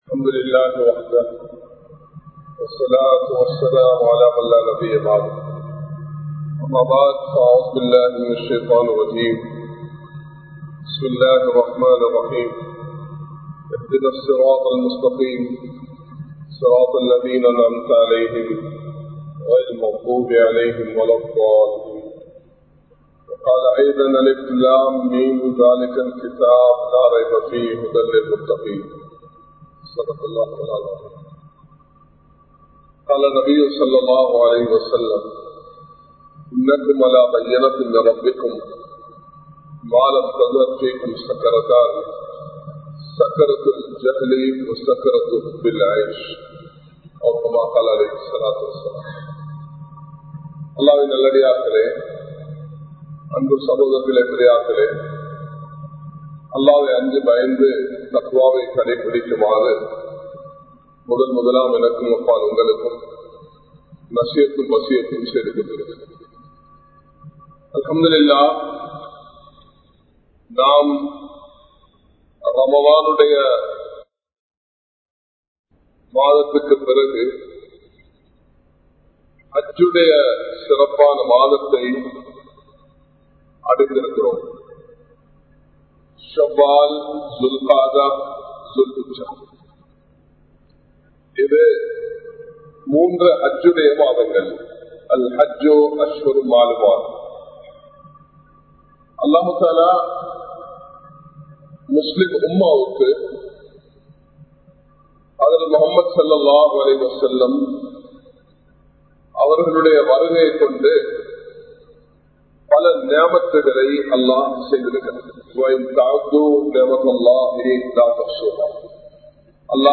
Em Ovvoruvarinathum Poruppuhal (எம் ஒவ்வொருவரினதும் பொறுப்புகள்) | Audio Bayans | All Ceylon Muslim Youth Community | Addalaichenai
Kollupitty Jumua Masjith